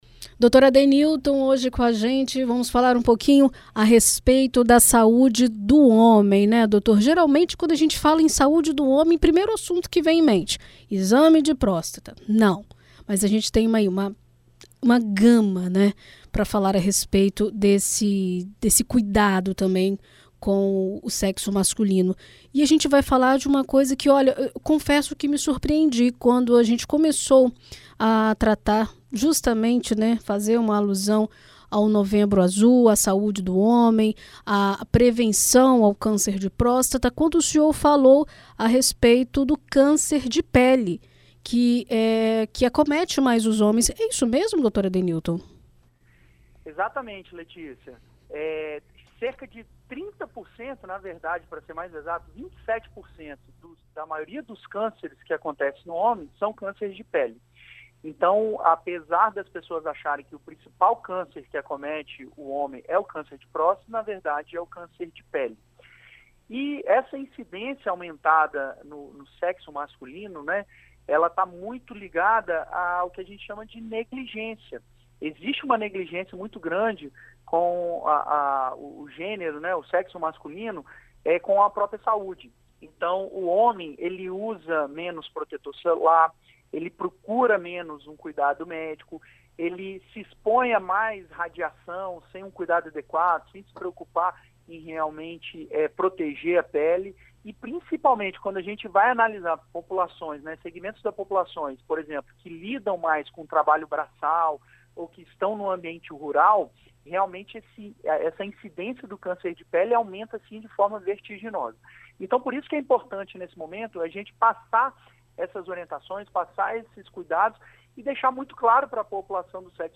Na coluna Visita Médica desta quinta-feira (17), na BandNews FM Espírito Santo